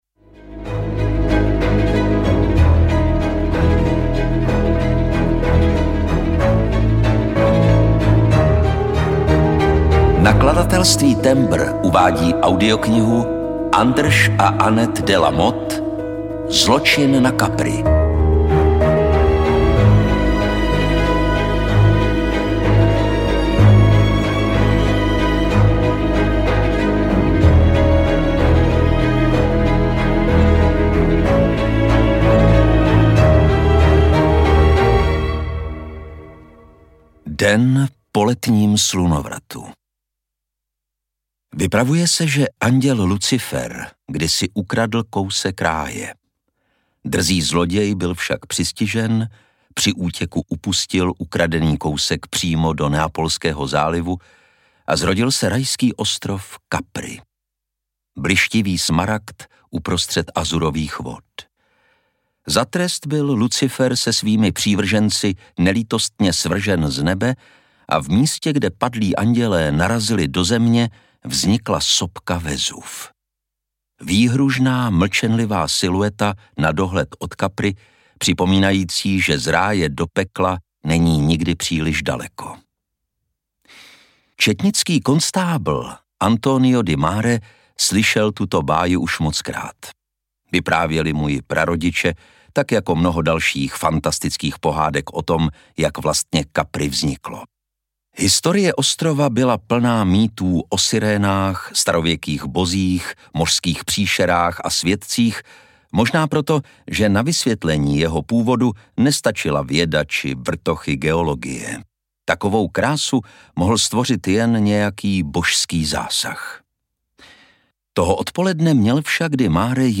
Zločin na Capri audiokniha
Ukázka z knihy
• InterpretLukáš Hlavica